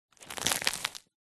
Звуки хлеба
Хруст отламывания горбушки батона